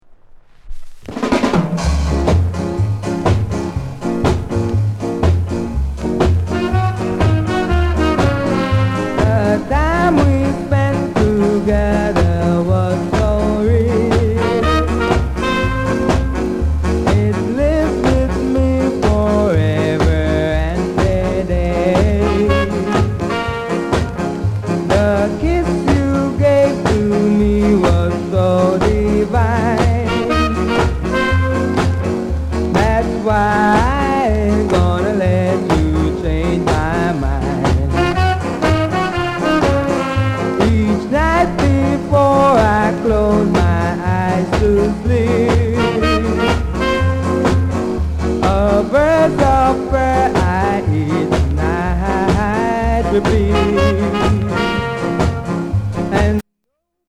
NICE SKA VOCAL